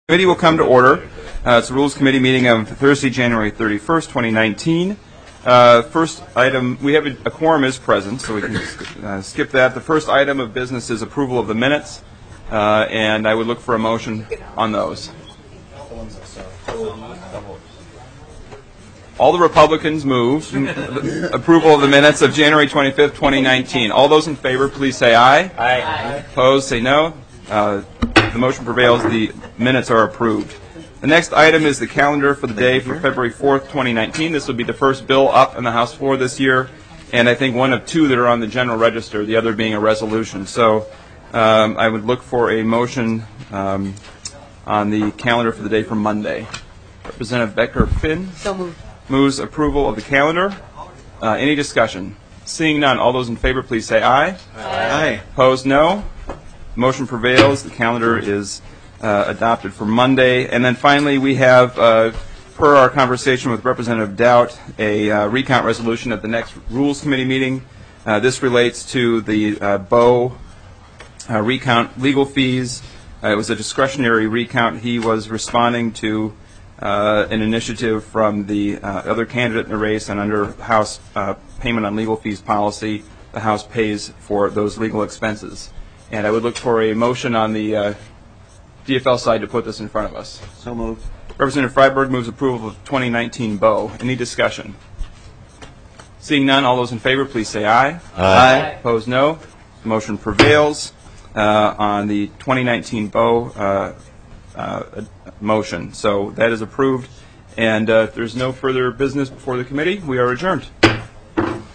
Chair: Rep. Ryan Winkler
Basement Hearing Room